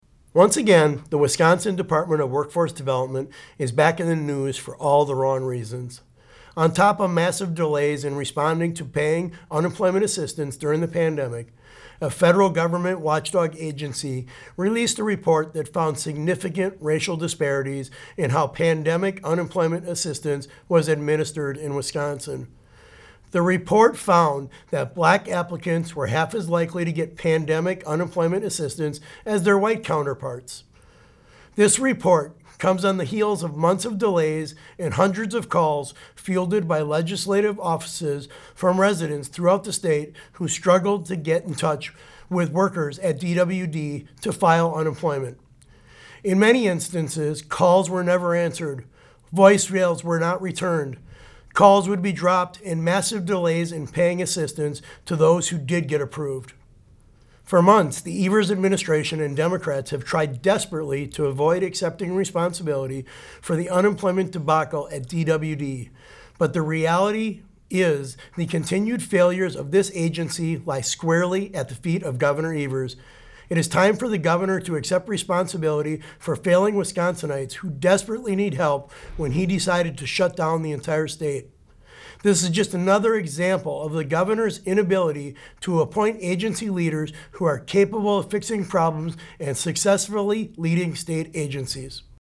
Weekly GOP Radio Address: Sen. Feyen says Evers administration and Dems are responsible for unemployment debacle at Dept. of Workforce Development - WisPolitics